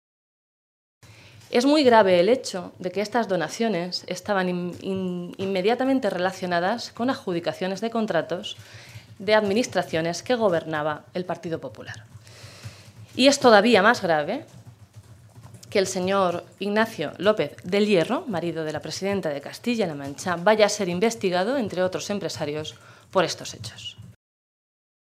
Blanca Fernández, diputada regional del PSOE de Castilla-La Mancha
Cortes de audio de la rueda de prensa